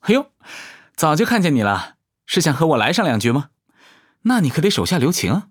【模型】GPT-SoVITS模型编号092_男-secs